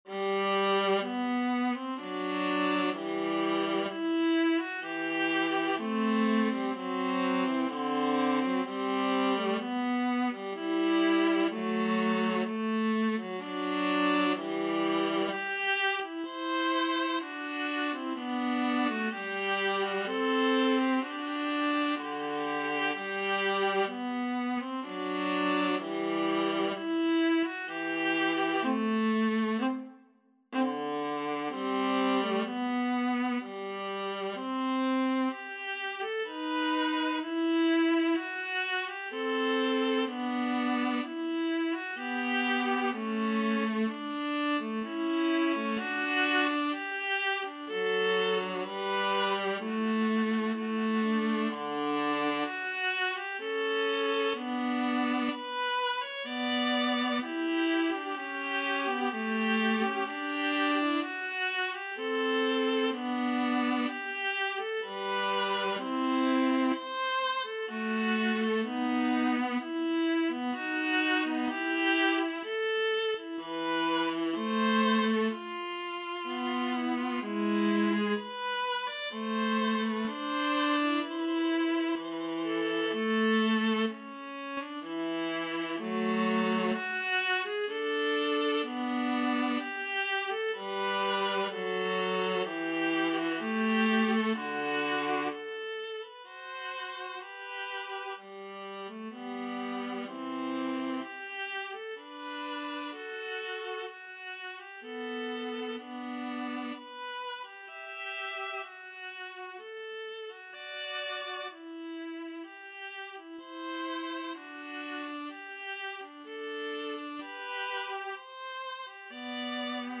Viola 1Viola 2
3/2 (View more 3/2 Music)
Classical (View more Classical Viola Duet Music)